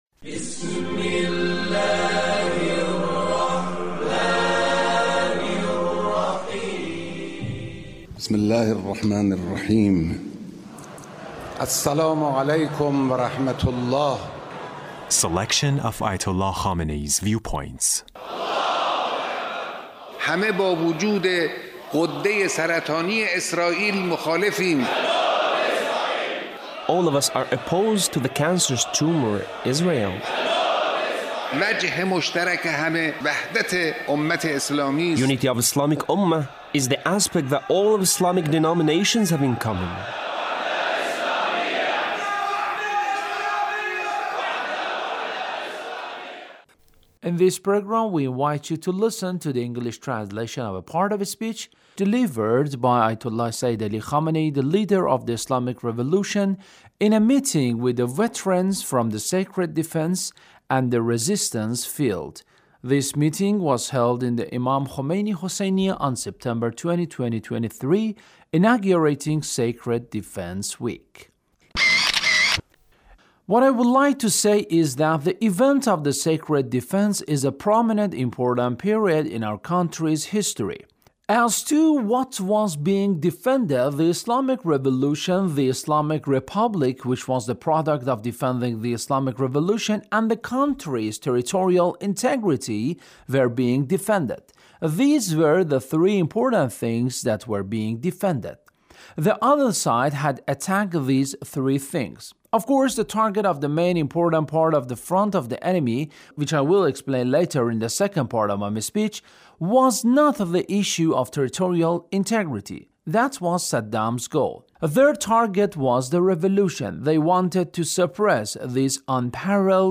Leader's Speech (1846)
Leader's Speech on Sacred Defense